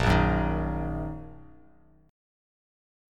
Bbsus4#5 chord